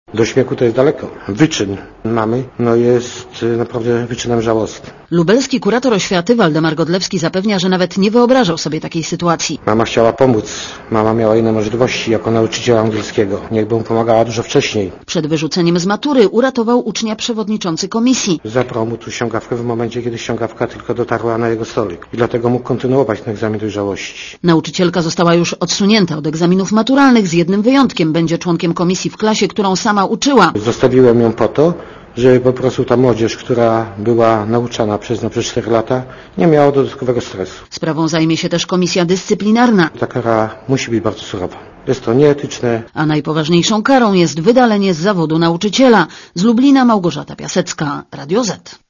Posłuchaj relacji reporerki Radia ZET (196 KB)